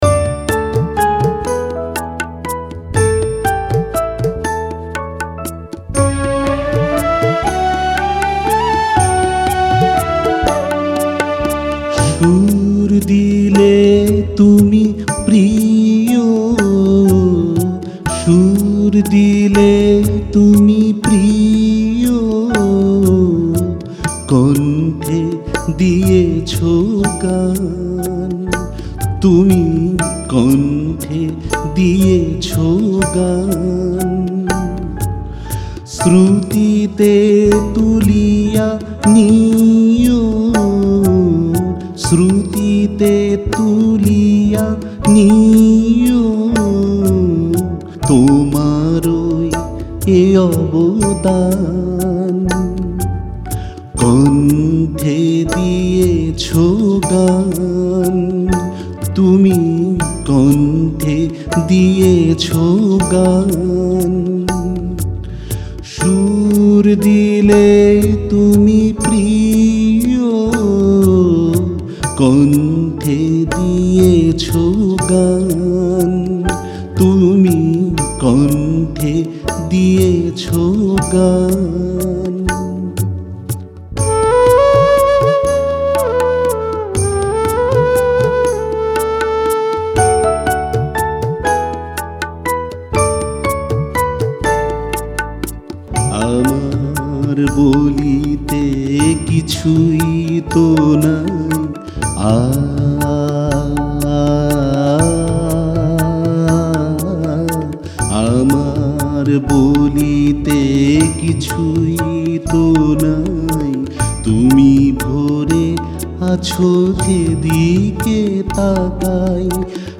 Music Dadra